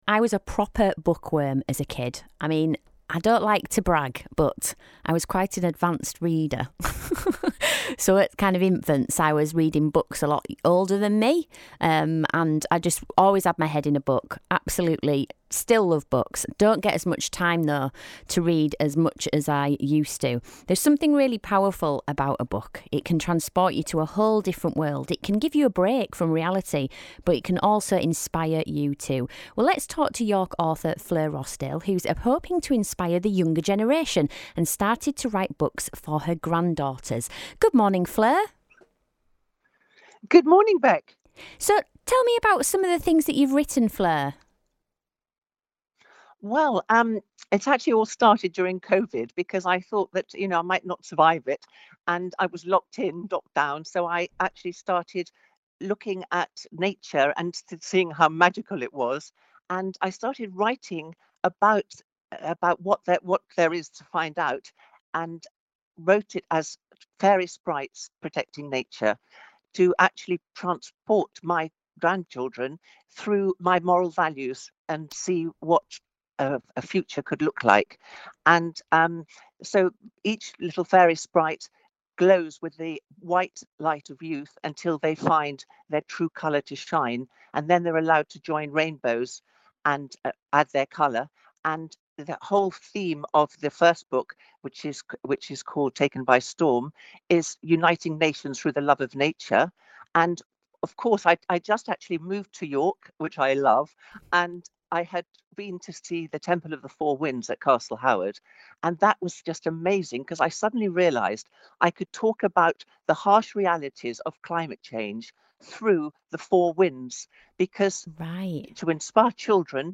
BBC Interview